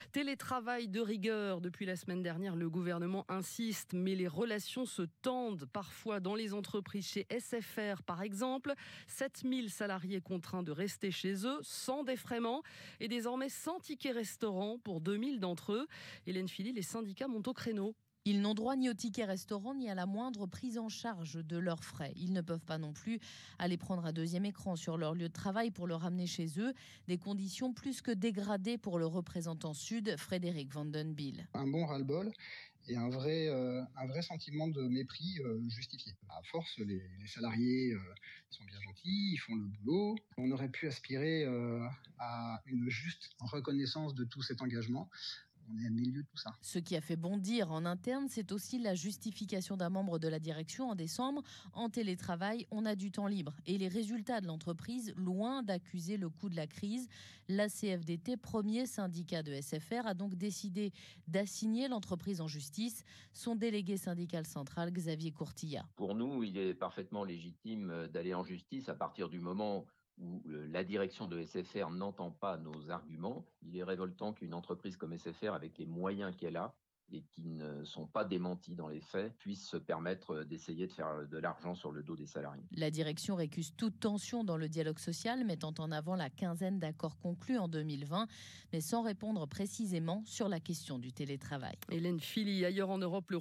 La CFDT continue d’être entendue sur le coût du télé-travail imposé pour les salariés et notamment via l’assignation pour faire reconnaître leurs droits. Ci-joint l’extrait du journal de France Inter de 8h de ce matin qui interroge Sud et la CFDT (à 8h 10 en replay sur le site de la radio).